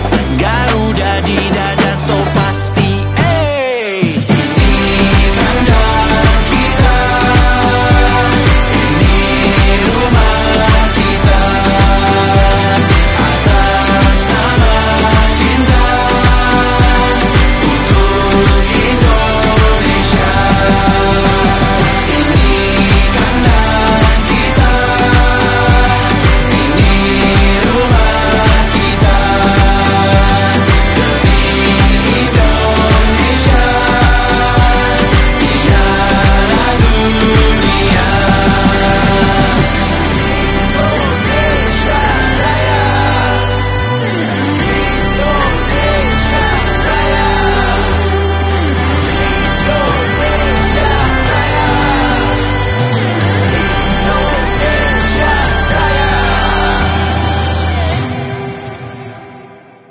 lagu megah berenergi tinggi
gitar